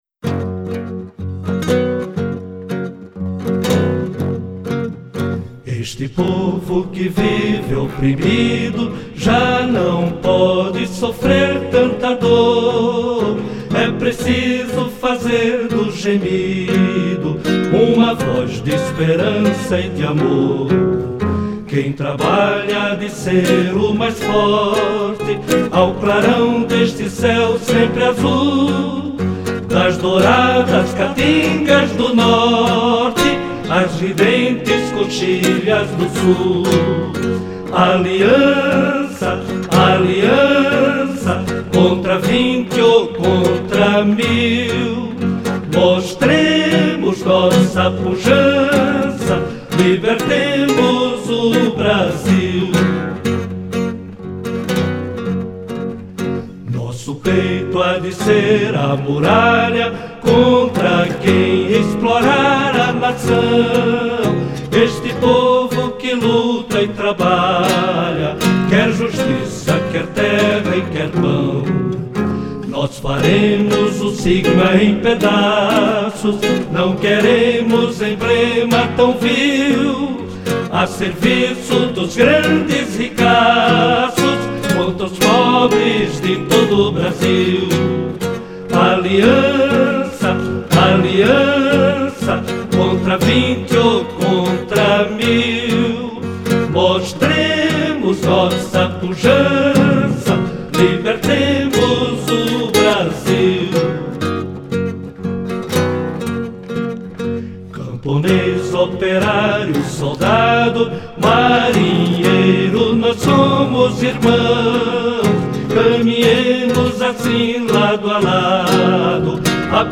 Música do Hymno da Republica
Hino da Aliança Nacional Libertadora em ritmo carnavalesco
com base no Hino da República